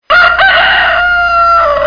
Le coq | Université populaire de la biosphère
il chante
le-coq.mp3